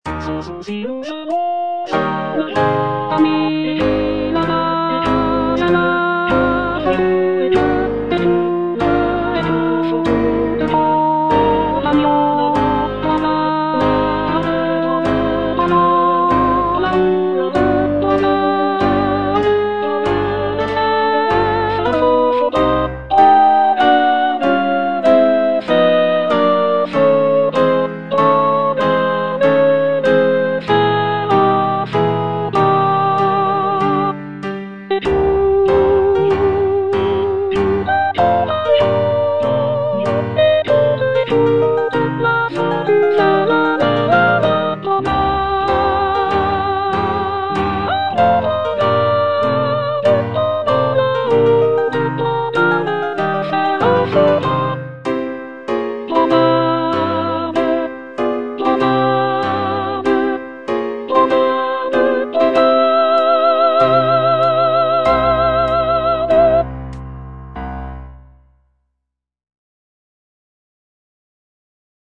G. BIZET - CHOIRS FROM "CARMEN" Ami, là-bas est la fortune (soprano I) (Voice with metronome) Ads stop: auto-stop